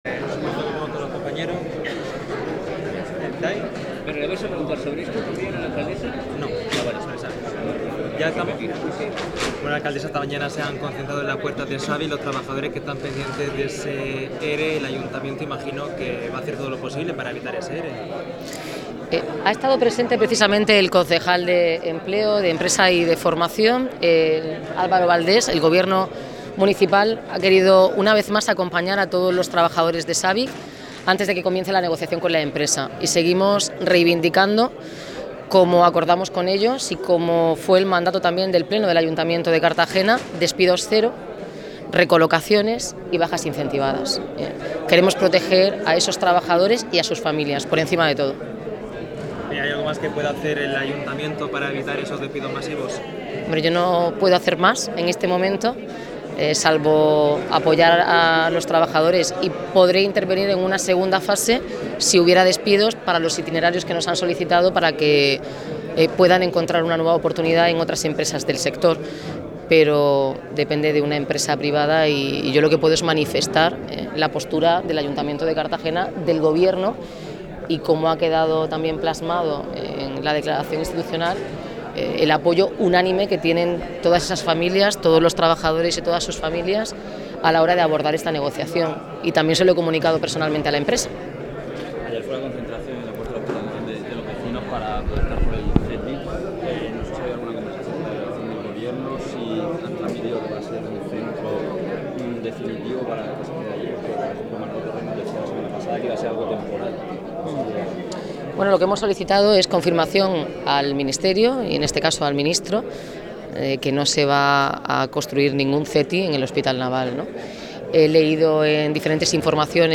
Este lunes han participado junto a los trabajadores en una concentraci�n que ha tenido lugar en la puerta de la factor�a de La Aljorra
Representantes de todos los grupos municipales del Ayuntamiento de Cartagena participaron este lunes, 30 de octubre, en la concentración que los trabajadores de Sabic han llevado a cabo en la puerta de la factoría de La Aljorra.